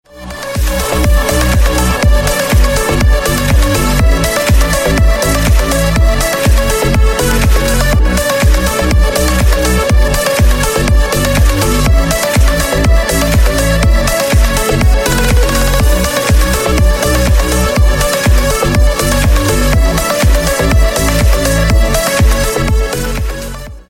Клубные Рингтоны » # Рингтоны Без Слов
Рингтоны Электроника